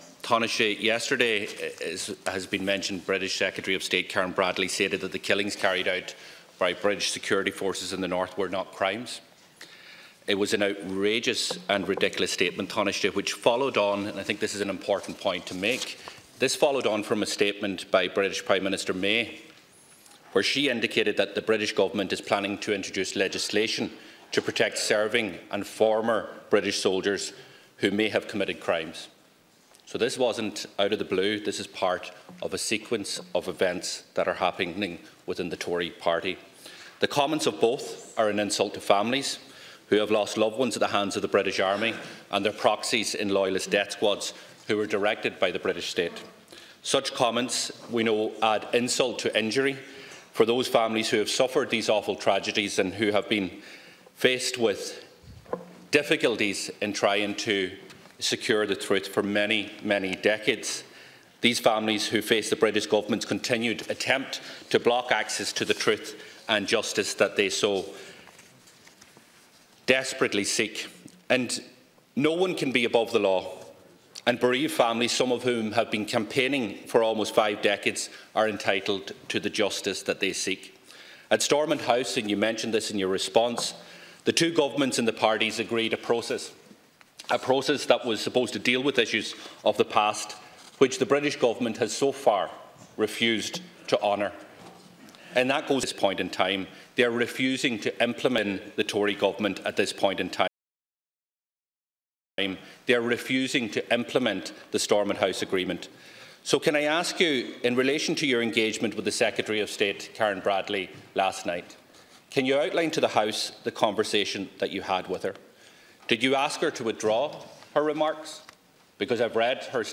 However, Deputy Doherty speaking in the Dail a short time ago said it was the content of her remarks not the language used that has caused widespread upset.